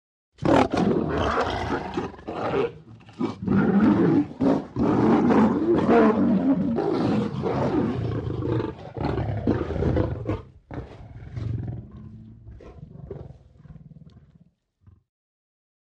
ANIMALS WILD: Male lions fighting.